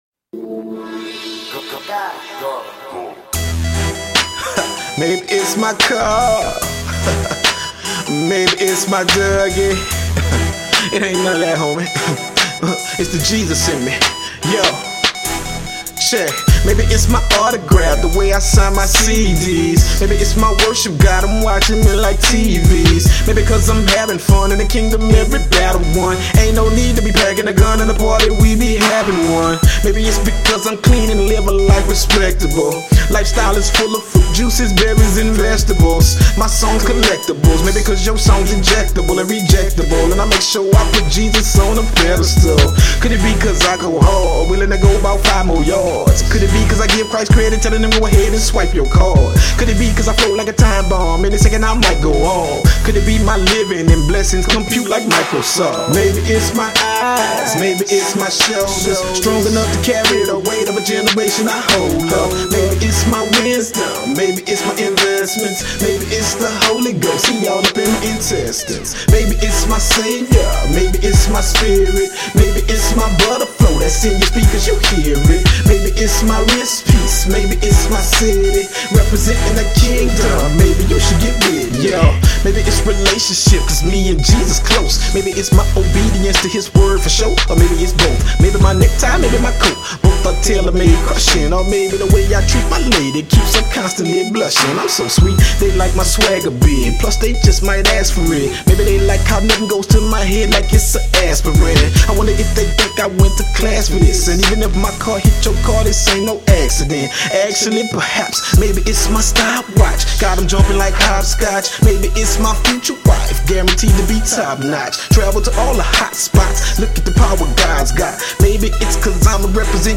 rapgospel.